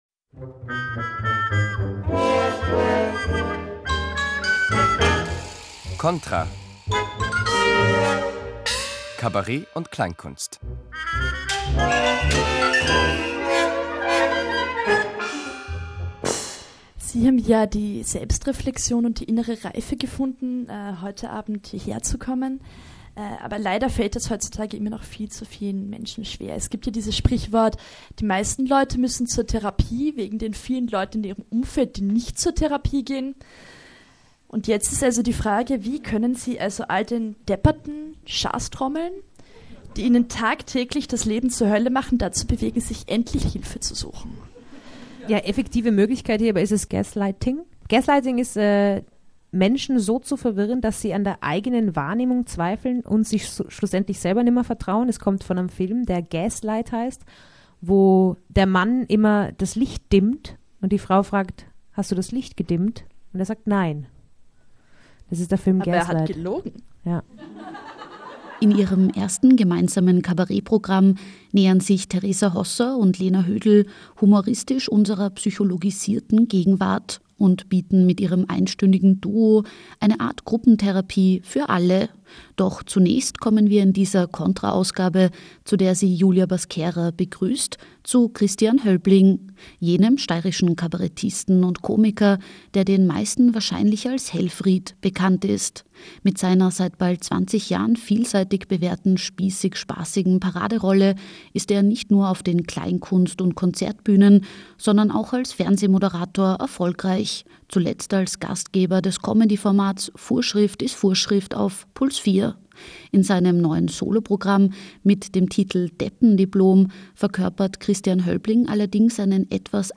ORF-Radiobeitrag